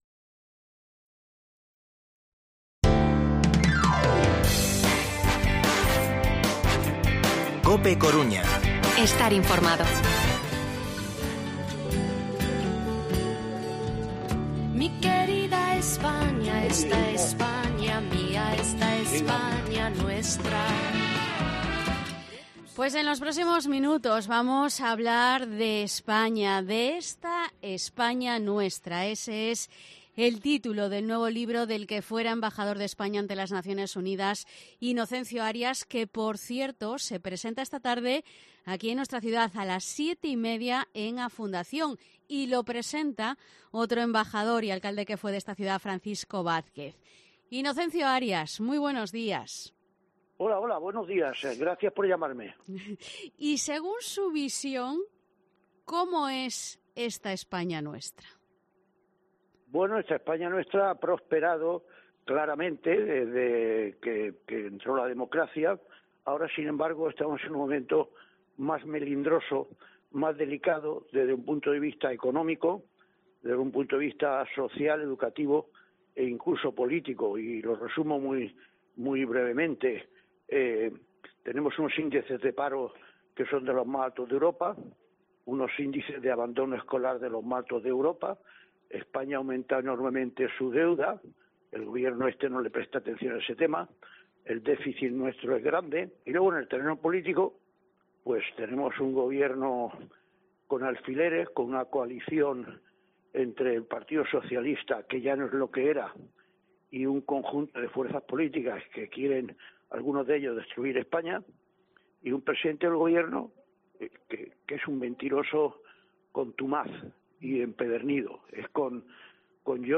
AUDIO: Entrevista Inocencio Arias. Presentación de su nuevo libro Esta España nuestra.